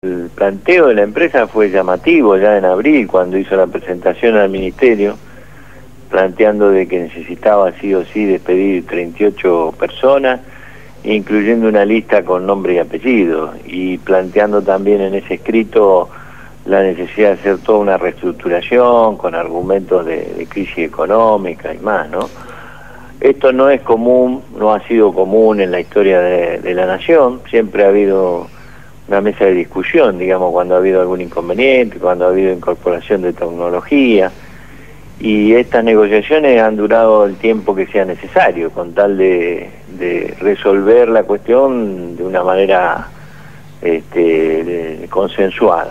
entrevistaron